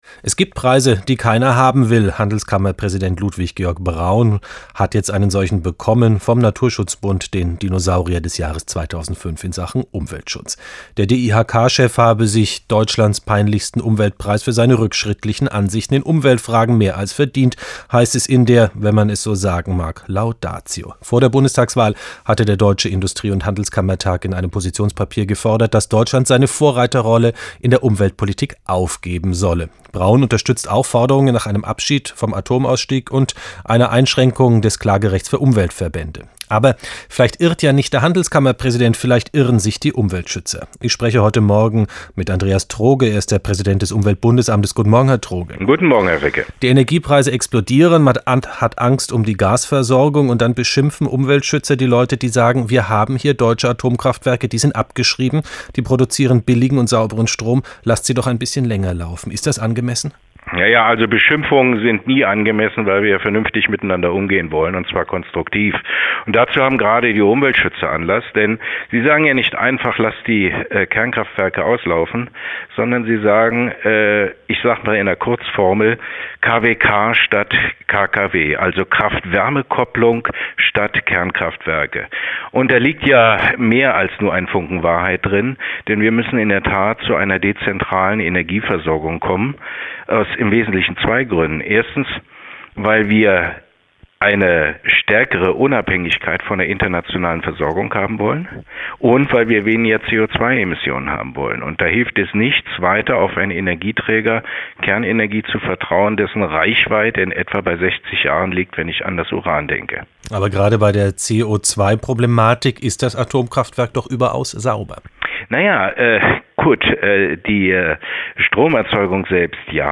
Radio-Interview Dr. Andreas Troge (UBA-Präsident 1995 - 2009) zu Atomkraft und erneuerbaren Energien (Deutschlandradio 2005, gekürzt)
2005_interview_andreas_troge.mp3